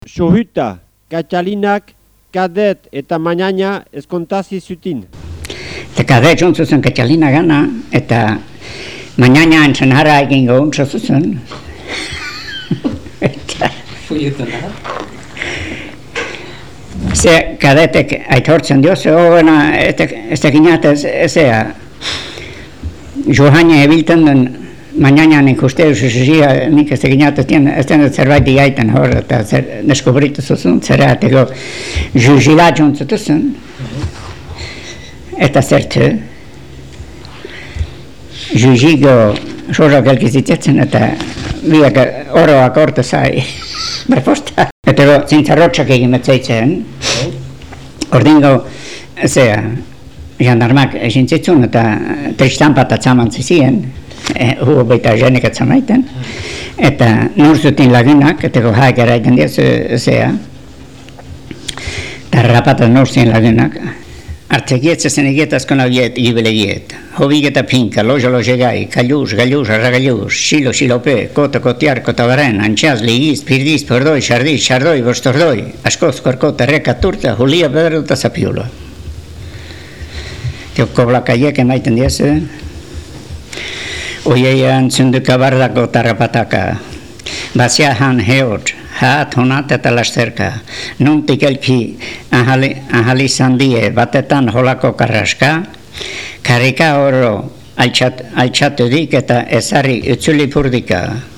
Senar-emazteen arteko kalapitak aipatzen ditu lekukoak berak idatzi zuen herri-teatroko testu honetan: Mañaña nexkatila eta Kadet mutikoa elkarrekin ezkontarazi nahi ditu Katalinak.